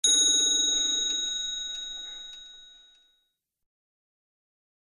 Clock 7, 1850, Strikes 1; Aristocrat's Mantelpiece Clock, Named Mysterious, Strikes 1 O'clock With Beautiful Small Glass Bell